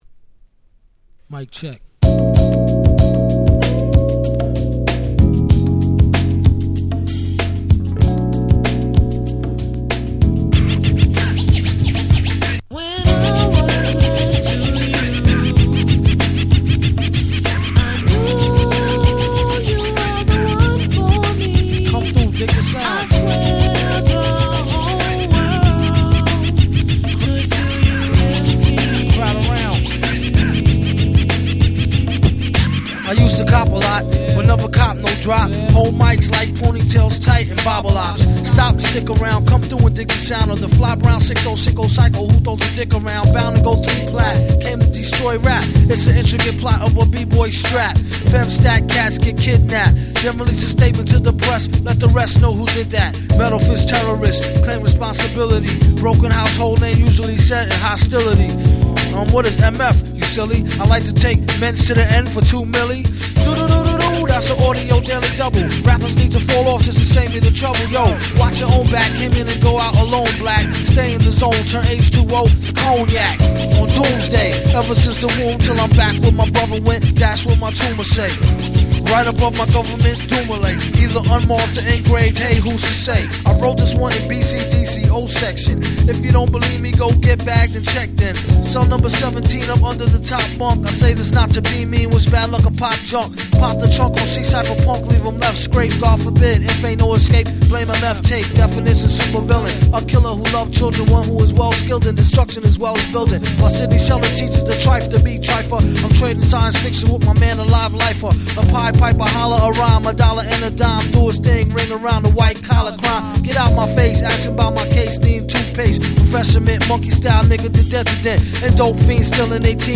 98' Super Nice Underground Hip Hop !!
アンダーグラウンド アングラ BOOM BAP ブーンバップ ドゥーム シャーデー